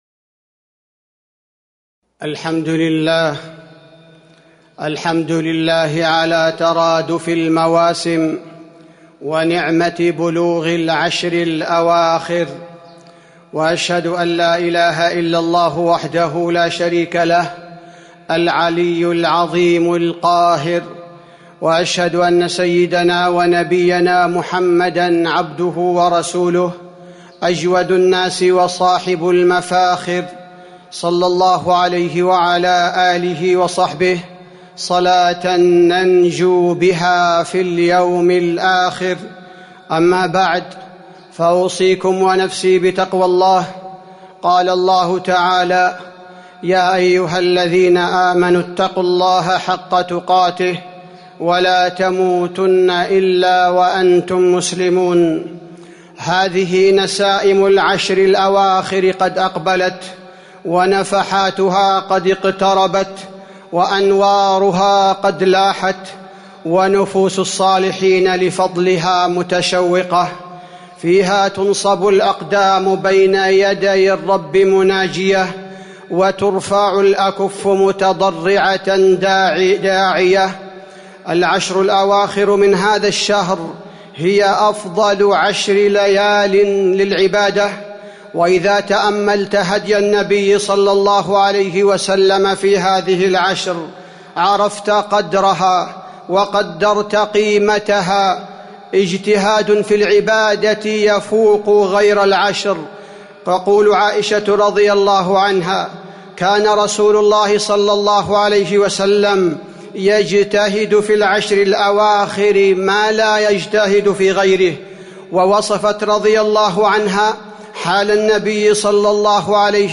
تاريخ النشر ١٨ رمضان ١٤٤٢ هـ المكان: المسجد النبوي الشيخ: فضيلة الشيخ عبدالباري الثبيتي فضيلة الشيخ عبدالباري الثبيتي الاستعداد للعشر الأواخر